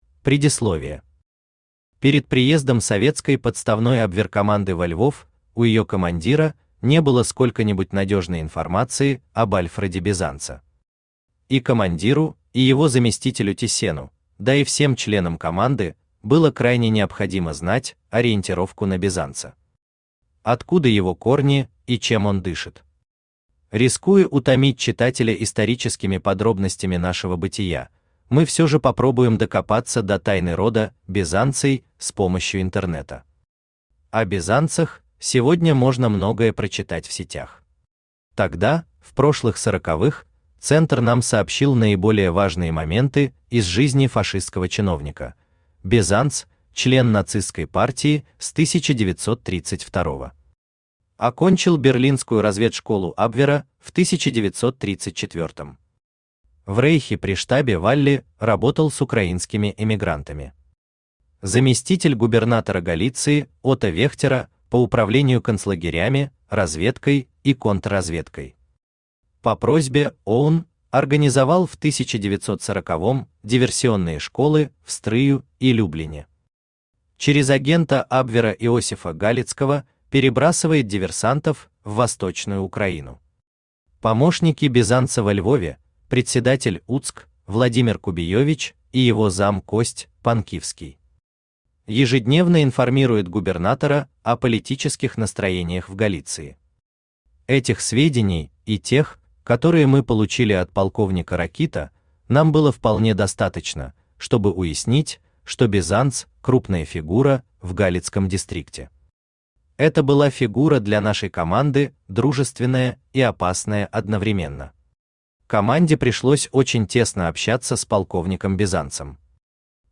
Аудиокнига Оружие Бизанца | Библиотека аудиокниг
Aудиокнига Оружие Бизанца Автор Вадим Гринёв Читает аудиокнигу Авточтец ЛитРес.